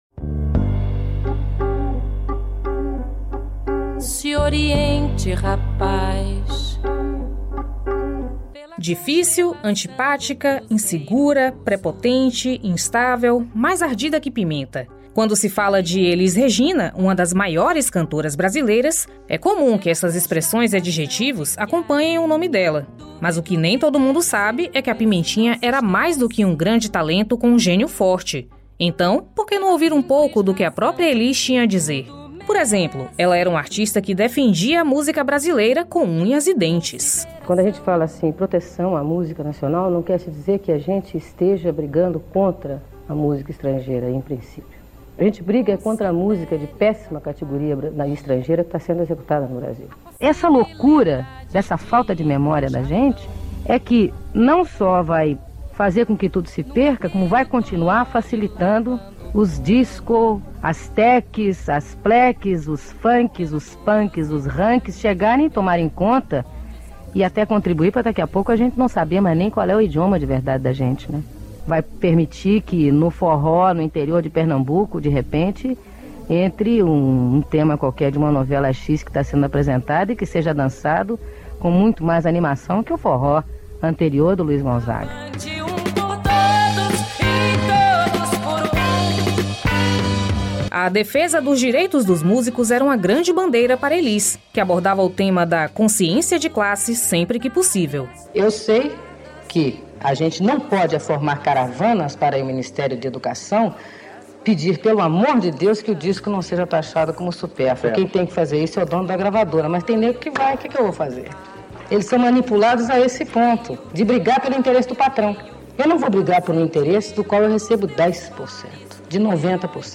Novas regras para o ensino a distância no Brasil serão apresentadas até fevereiro. A informação foi divulgada, nesta terça-feira (14), pelo ministro da Educação, Camilo Santana, em entrevista ao programa “Bom Dia, Ministro” da EBC.